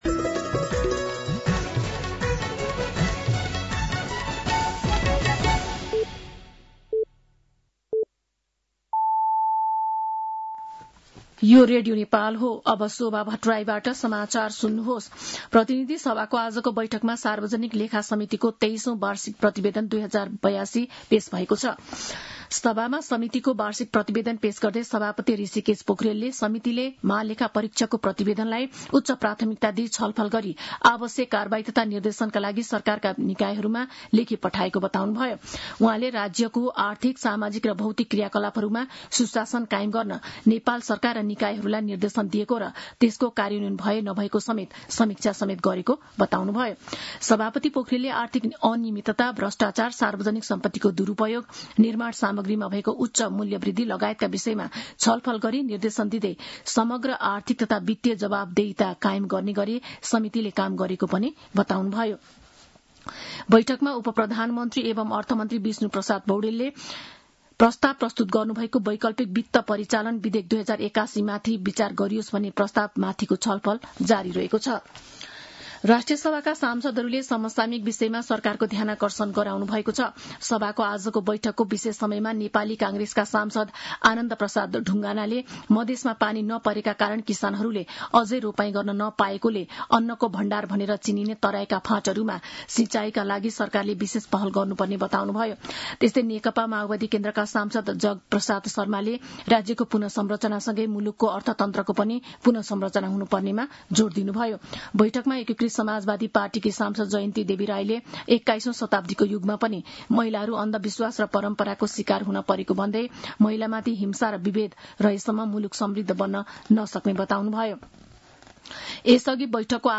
साँझ ५ बजेको नेपाली समाचार : ३१ असार , २०८२
5pm-news-3-31.mp3